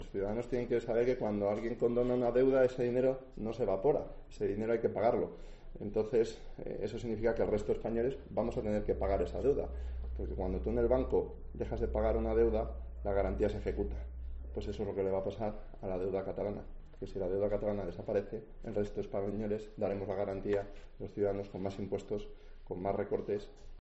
Héctor Palencia, diputado del PP por Ávila